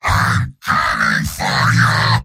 Giant Robot lines from MvM. This is an audio clip from the game Team Fortress 2 .
Heavy_mvm_m_yell8.mp3